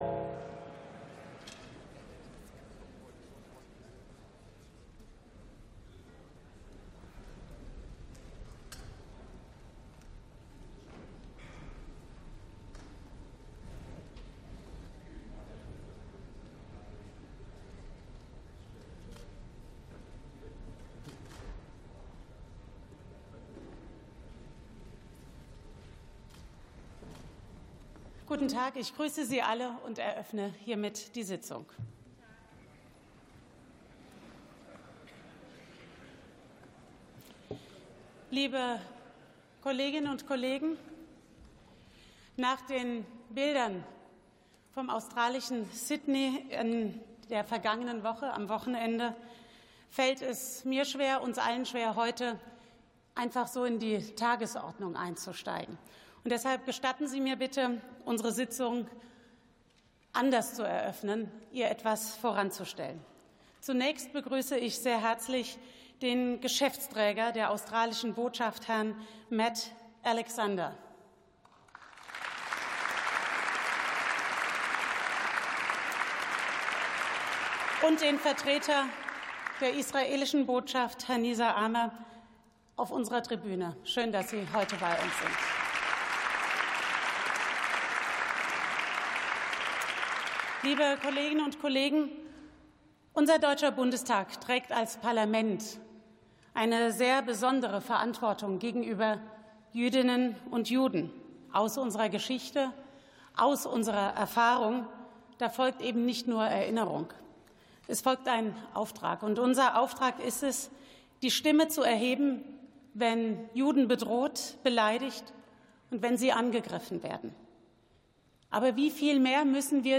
49. Sitzung vom 17.12.2025. TOP Sitzungseröffnung: